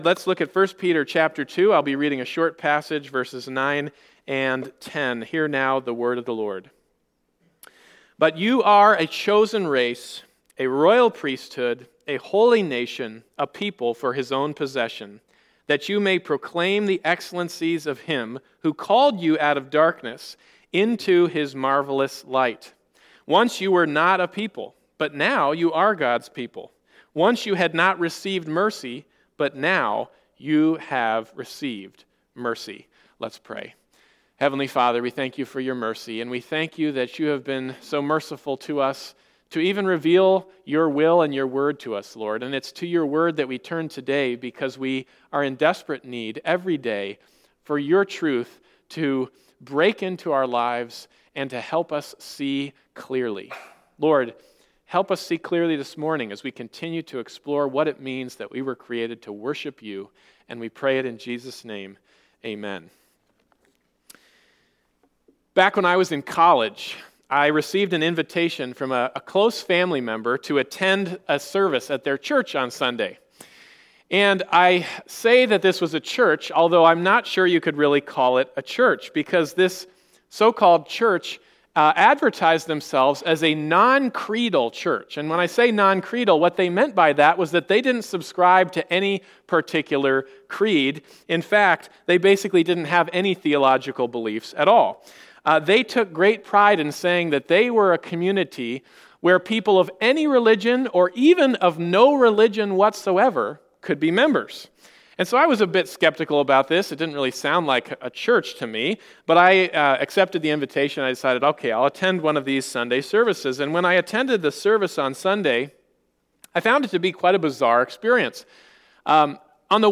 1 Peter 2:9-10 Service Type: Sunday Morning Service « What is Worship?